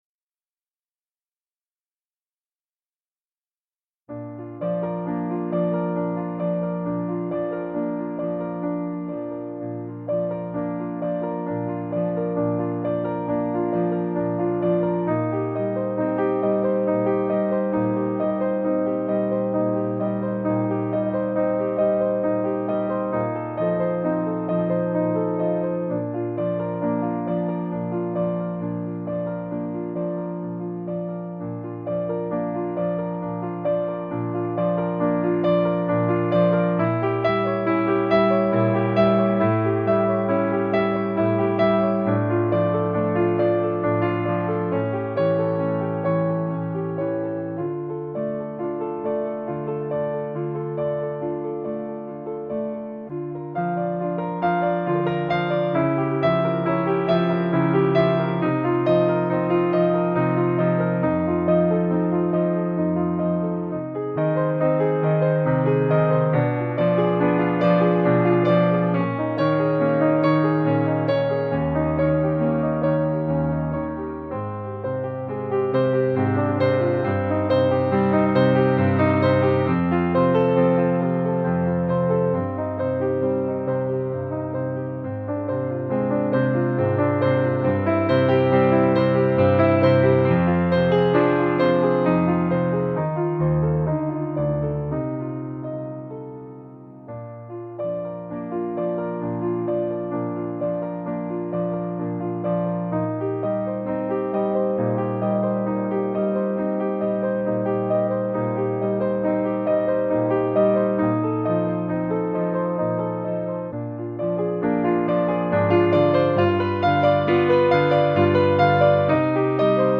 The Swan Piano Only